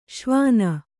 ♪ śvāna